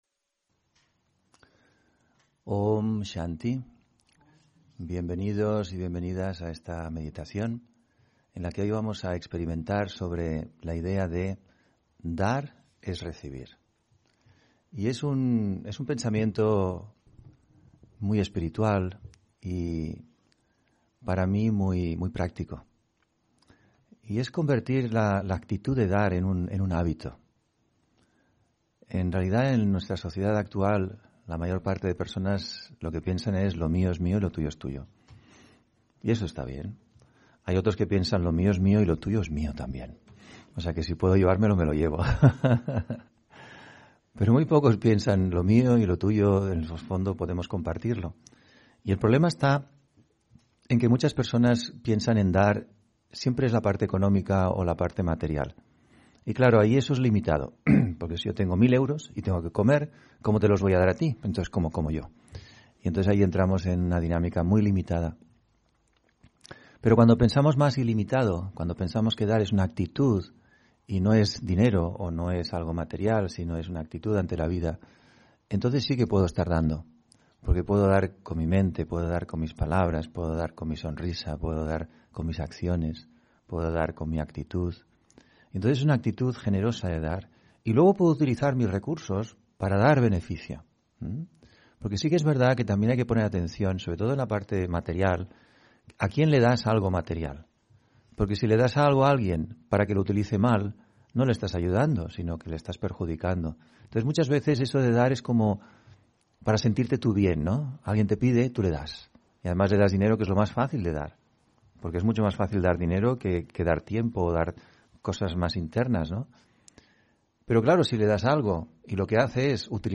Meditación de la mañana: Conecta con tu potencial